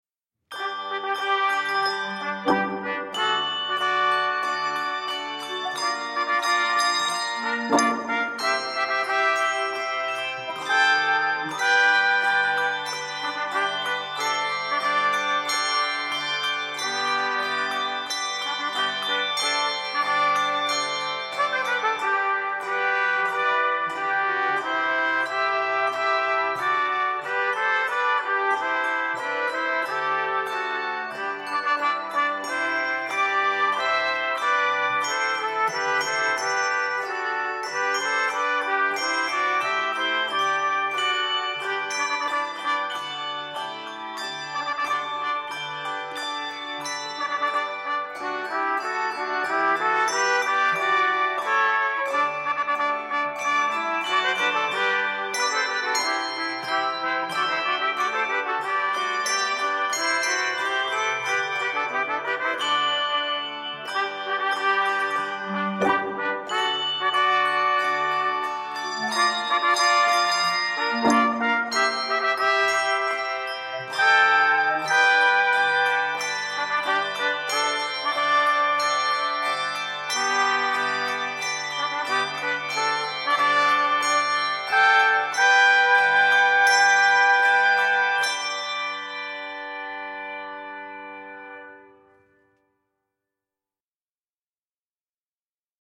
handbell music